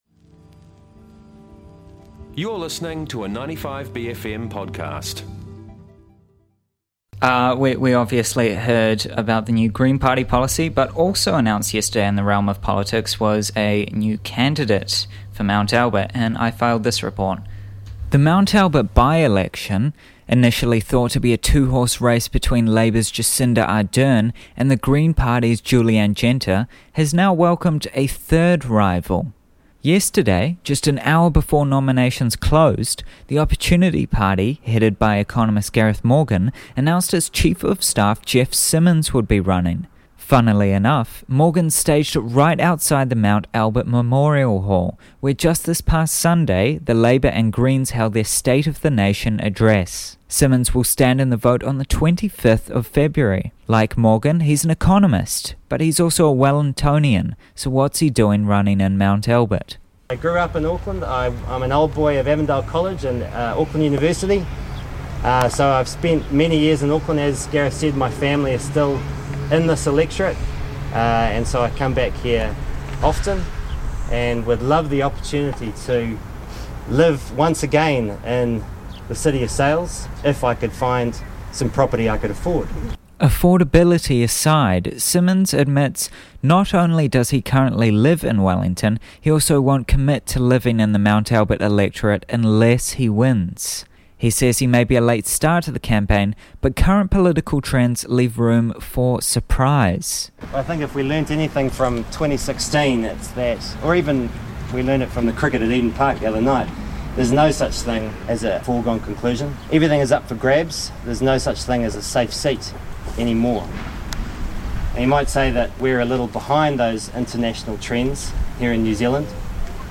goes to the announcment of new Opportunity party for the Mt Albert by-election.